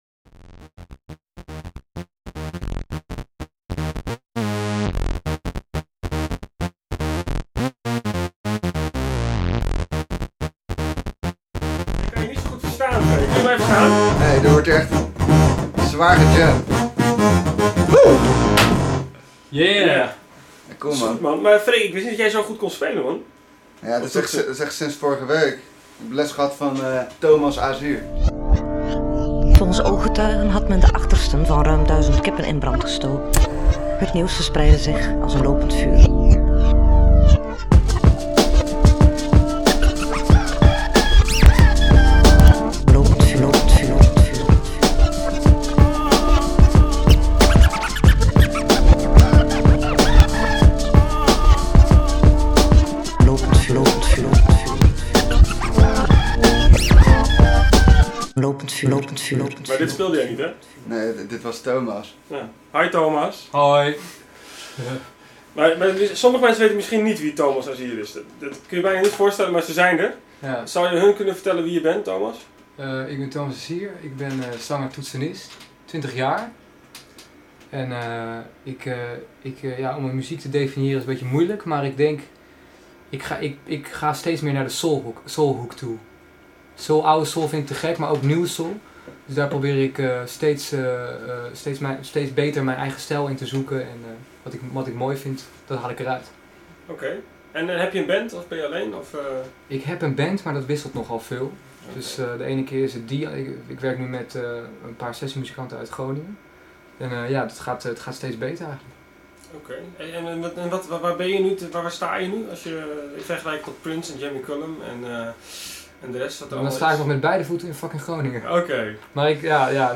live opgenomen in de veel te kleine studenten kamer
Soul!
op contrabas